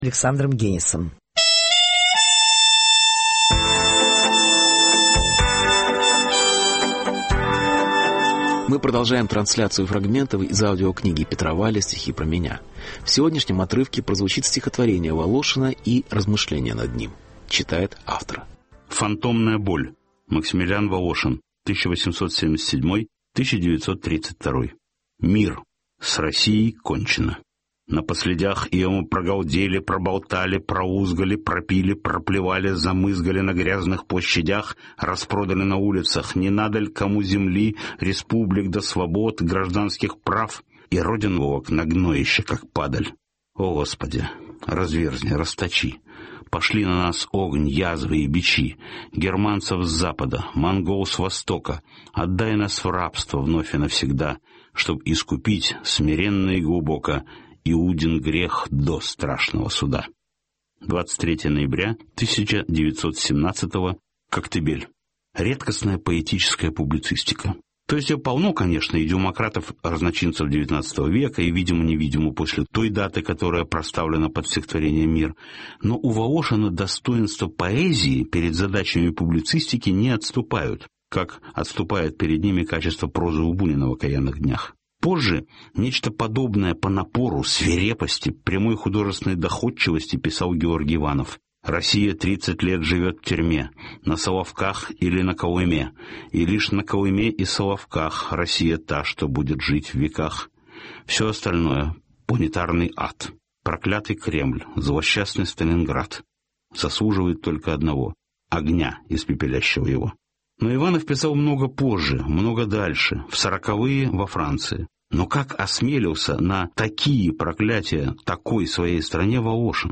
Фрагмент из аудио-книги Петра Вайля «Стихи про меня»: Волошин.